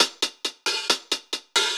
Index of /musicradar/ultimate-hihat-samples/135bpm
UHH_AcoustiHatA_135-05.wav